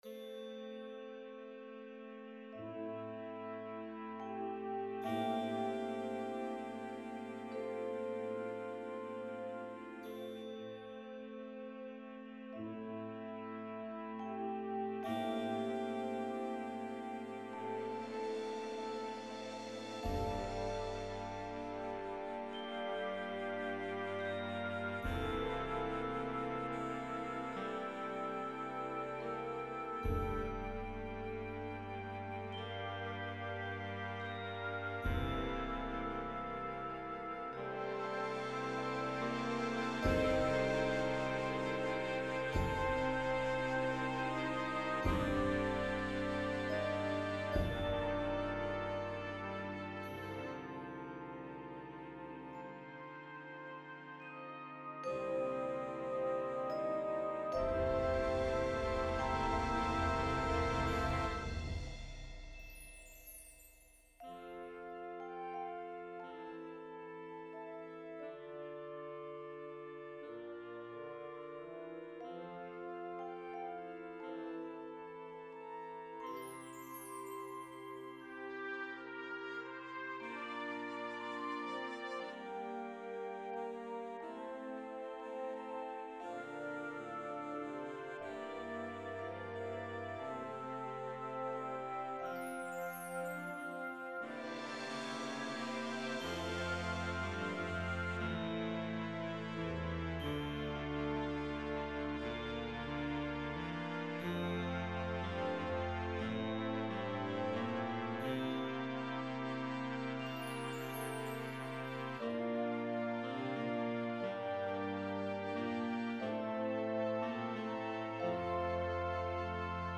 Concert Band
Woodwinds
Brass
Percussion
Flute
Oboe
Bass Clarinet
Bassoon
ATB Saxophones
Trumpet
F Horn
Trombone
Euphonium
Tuba
Glockenspiel
Vibraphone
Bass Drum
Mark tree & Triangle